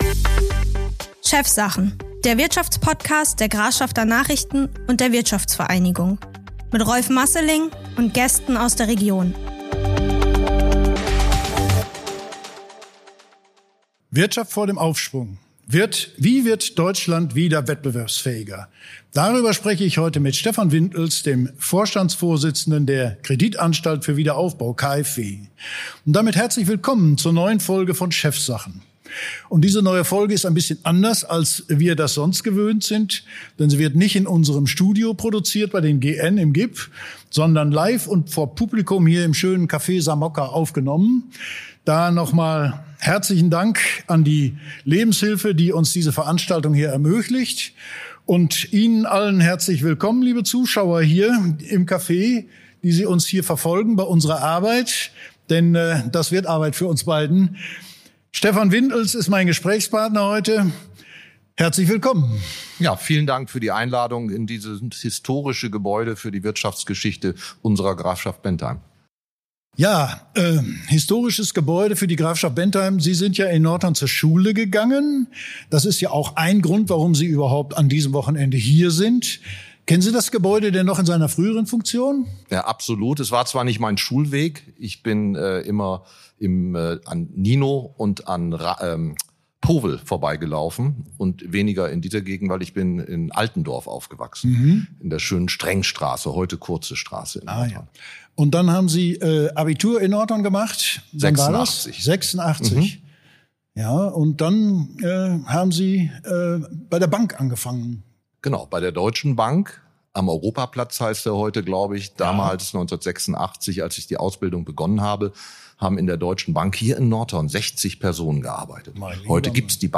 Antworten gibt es in der neunten Episode der zweiten Staffel von „Chefsache(n)" – erstmals live vor Publikum im Samocca Nordhorn aufgezeichnet.